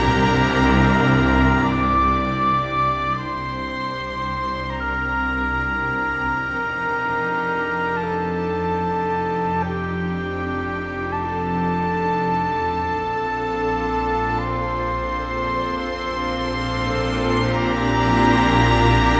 Rock-Pop 17 Orchestra 01.wav